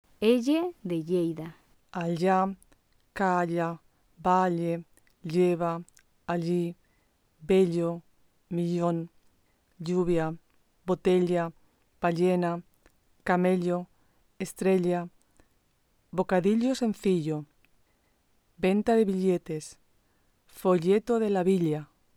/ʎ/ Lateral palatal sonora
El aire sale por dos pequeñas aberturas laterales a ambos lados de la boca a la altura de los últimos molares.
• < ll > llave [ʎáβe], lluvia [ʎúβja], llamar [ʎamáɾ]
[λ] de Lleida
Pronunciacion_31_ll_.mp3